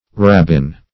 rabbin - definition of rabbin - synonyms, pronunciation, spelling from Free Dictionary Search Result for " rabbin" : The Collaborative International Dictionary of English v.0.48: Rabbin \Rab"bin\ (r[a^]b"b[i^]n), n. [F.]